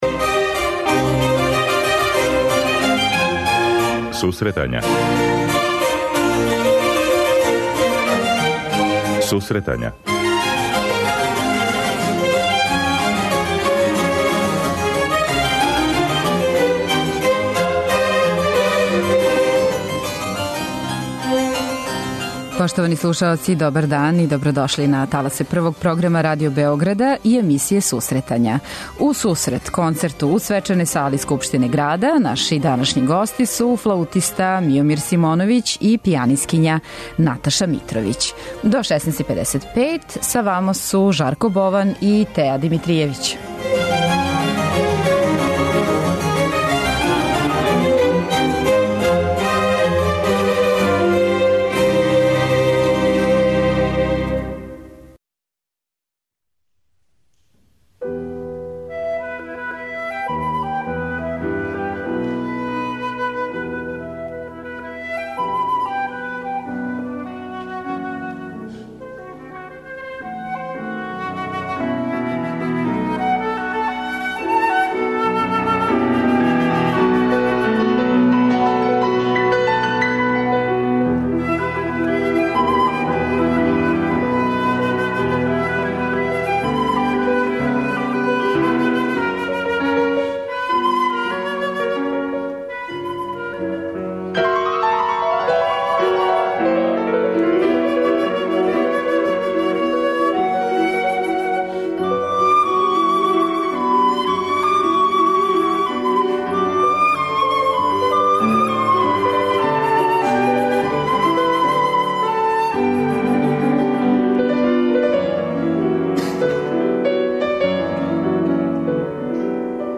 У сусрет концерту у Свечаној сали Скупштине града, гости емисије биће флаутиста
Емисија за оне који воле уметничку музику.